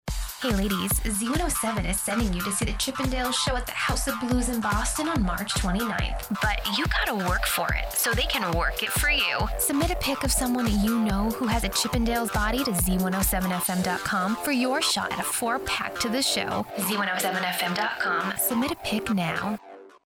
Commercial VO Demo - Quirky & Young Female Voice
Commercial VO Demo
Z107 Chippendales Radio VO Promo